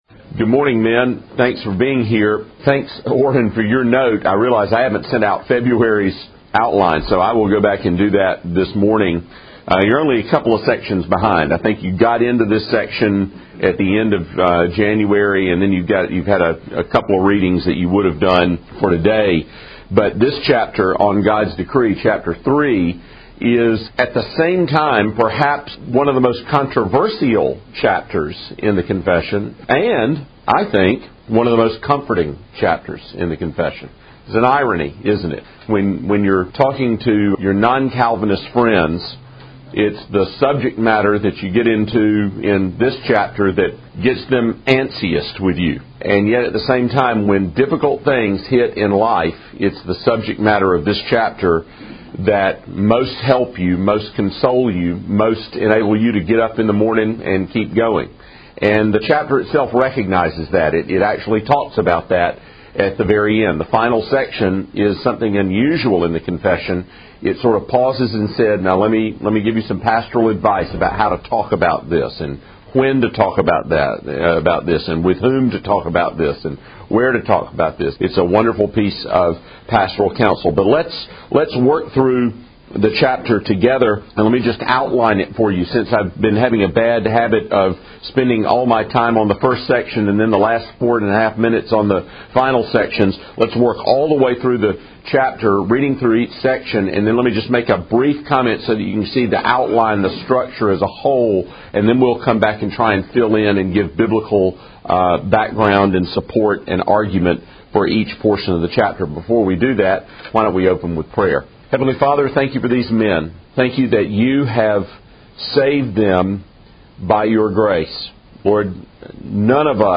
No attempt has been made, however, to alter the basic extemporaneous delivery style, or to produce a grammatically accurate, publication-ready manuscript conforming to an established style template.
WCF_Lecture_4.mp3